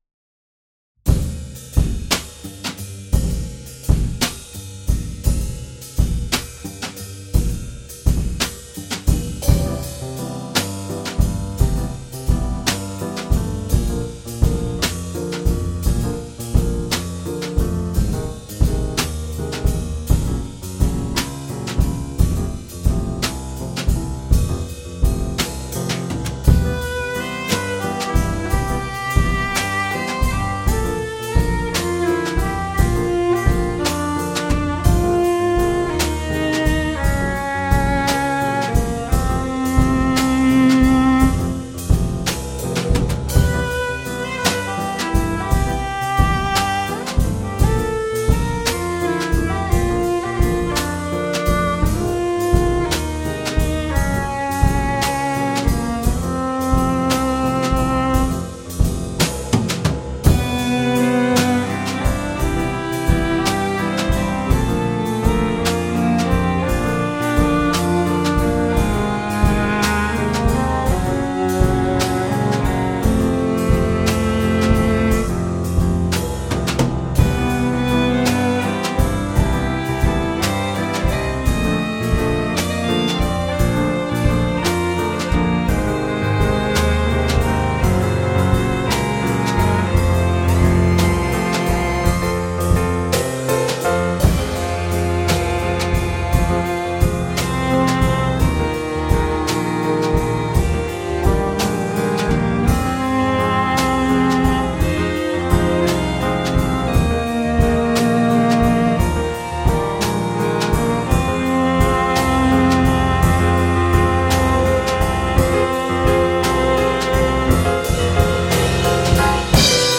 Anyway, here it is as a jazz ensemble.